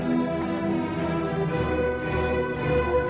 I. ALLEGRO MAESTOSO
Mi mineur. 3/4.